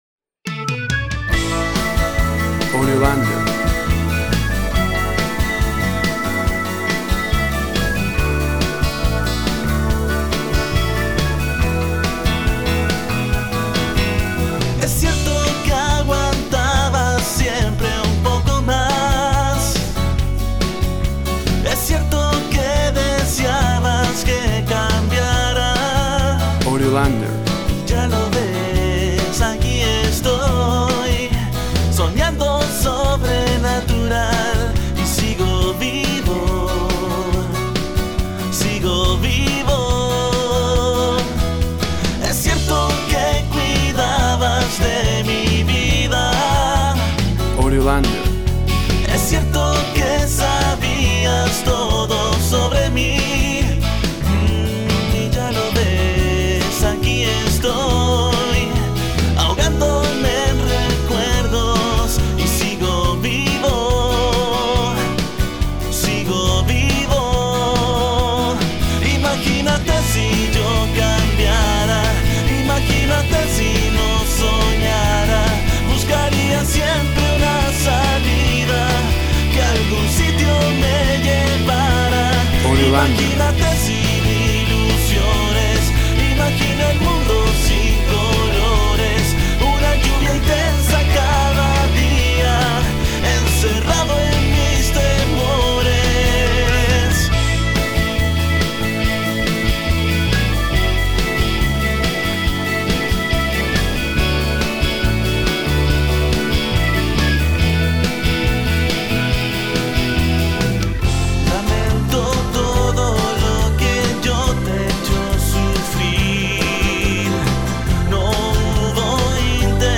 Tema POP
Tempo (BPM) 150